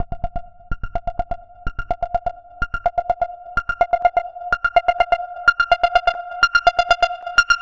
Heart Monitor Bb126.wav